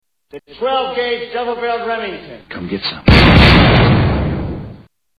12 Gauge Shotgun 2